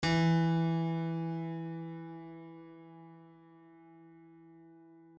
piano-sounds-dev
e2.mp3